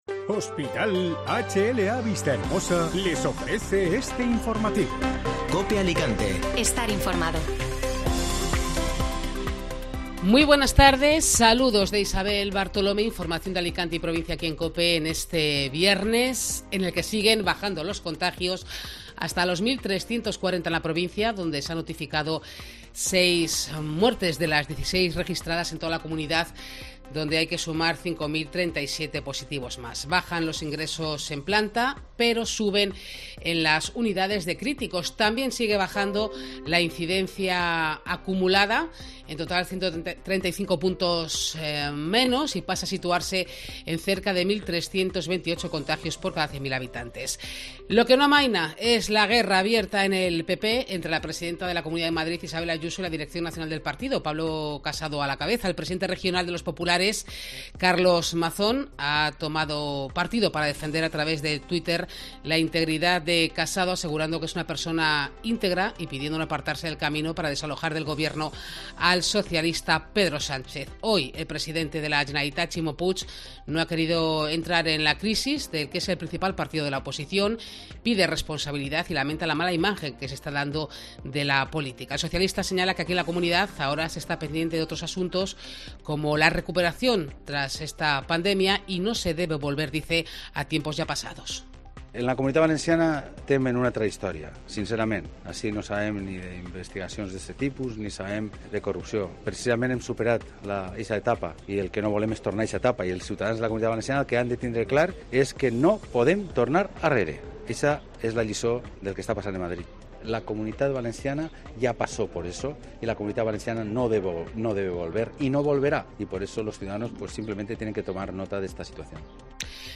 Informativo Mediodía COPE Alicante (Viernes 18 de febrero)